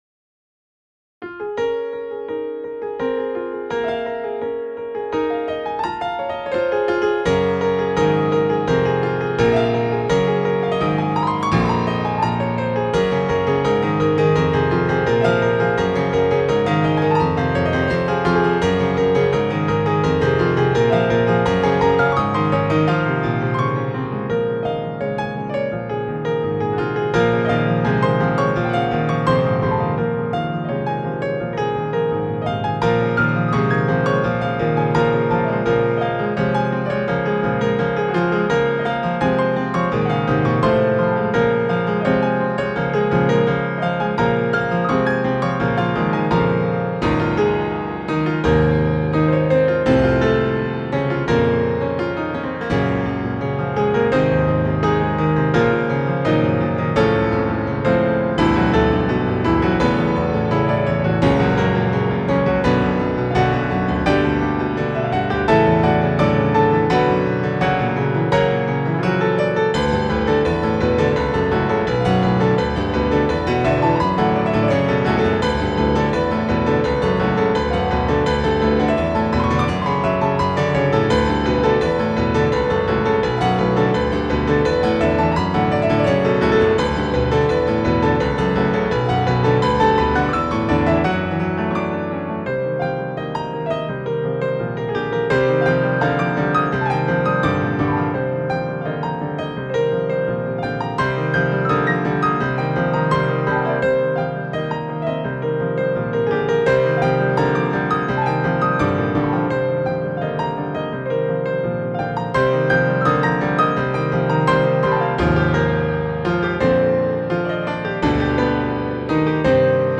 ARREGLO
PIANO
PIANO ARRANGEMENT